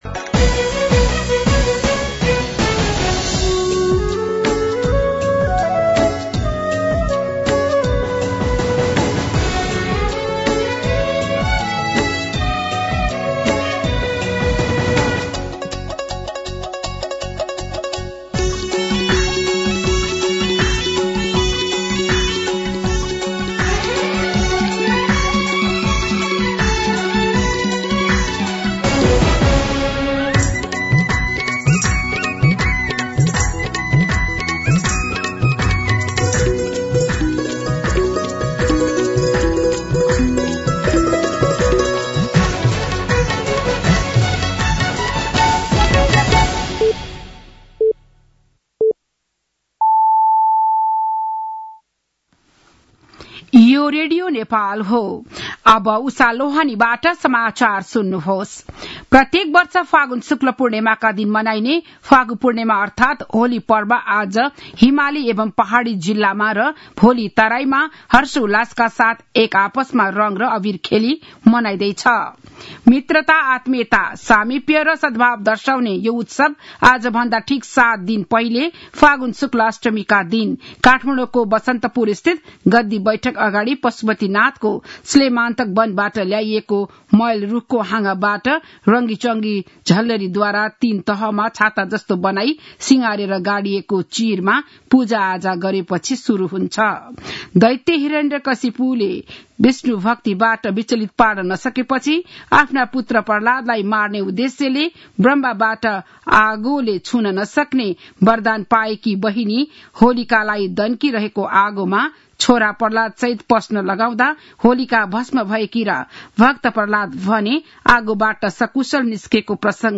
बिहान ११ बजेको नेपाली समाचार : ३० फागुन , २०८१
11-am-news-2.mp3